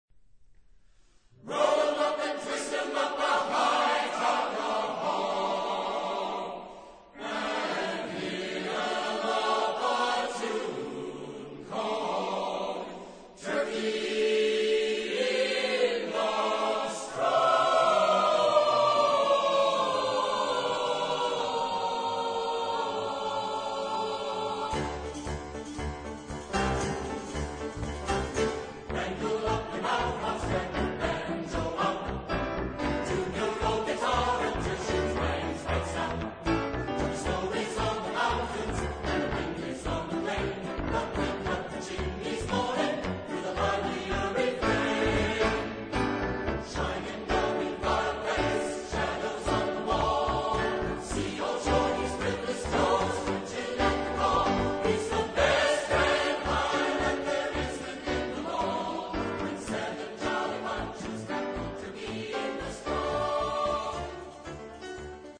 Genre-Style-Forme : Profane
Type de choeur : SAATTB  (6 voix mixtes )
Solistes : Baritone (1)  (1 soliste(s))
Tonalité : ré majeur ; mi majeur ; fa majeur